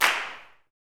CLAPSUTC9.wav